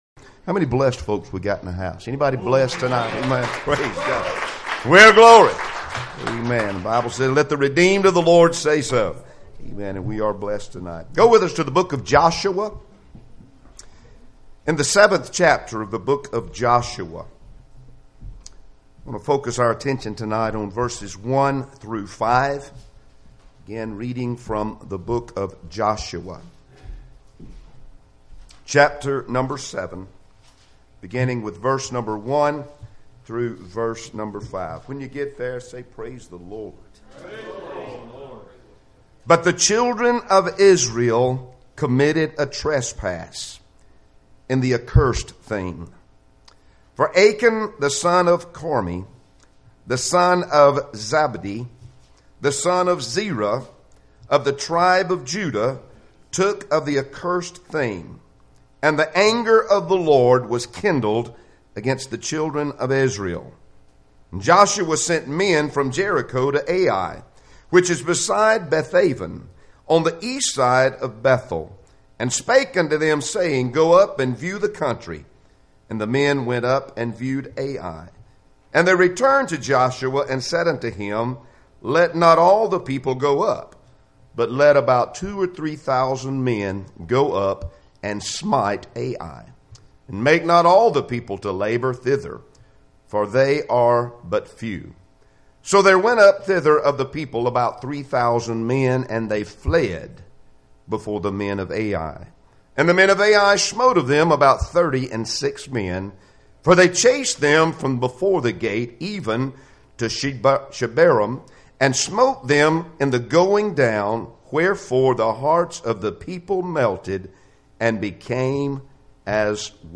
Passage: Joshua 7:1-5 Service Type: Sunday Evening Services Topics